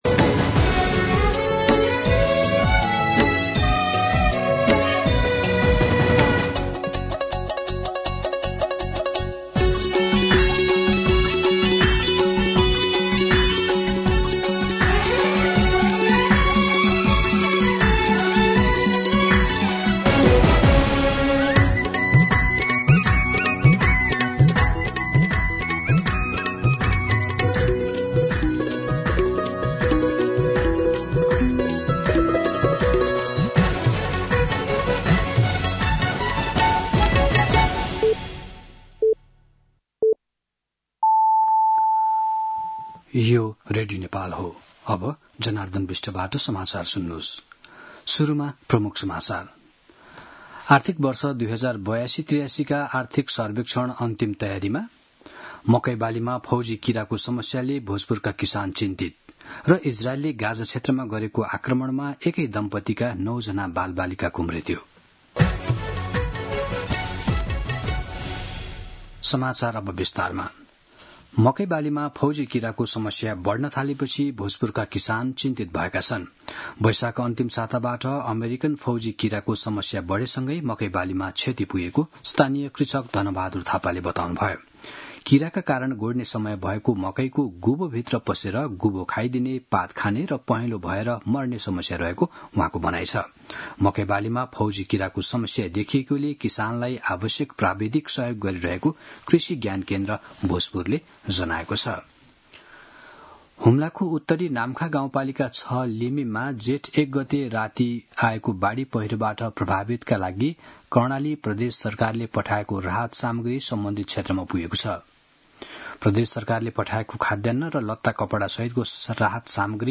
दिउँसो ३ बजेको नेपाली समाचार : ११ जेठ , २०८२